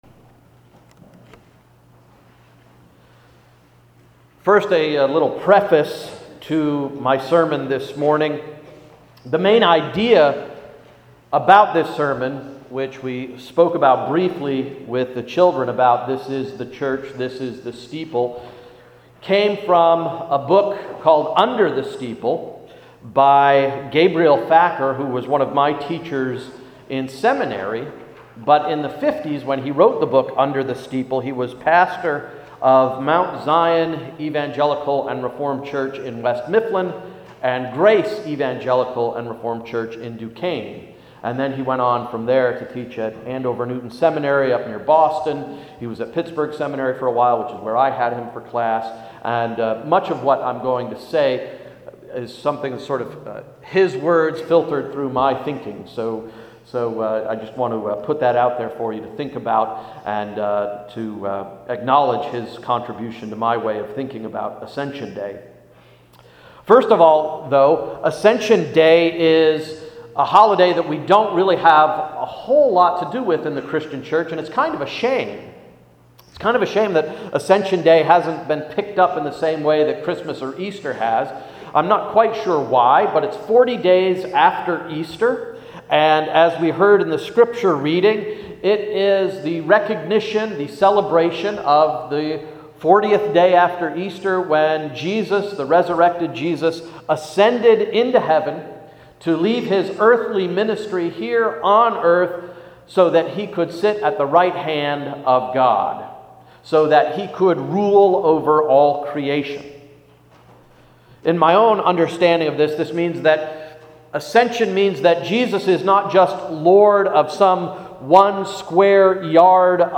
– Emmanuel Reformed Church of the United Church of Christ